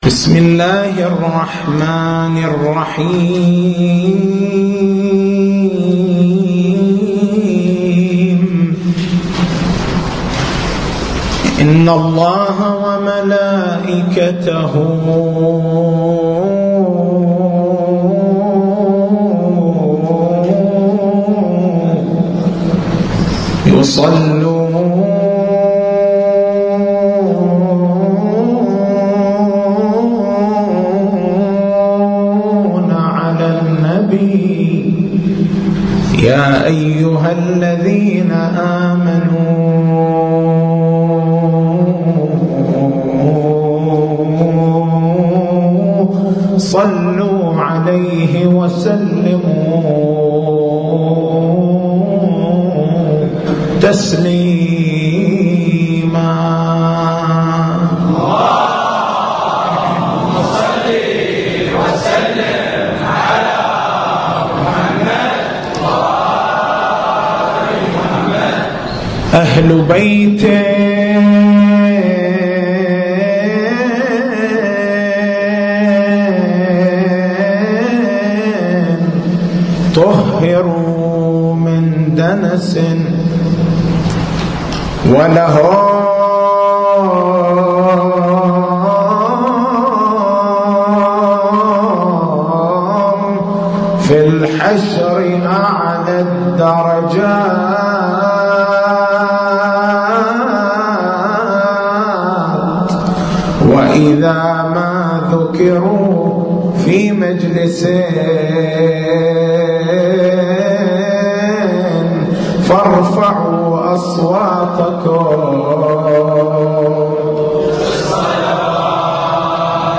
تاريخ المحاضرة: 15/09/1434 نقاط البحث: حبيب الله ما هو معنى الحب الإلهي؟ ما هو الفرق بين الحبّ والخلّة؟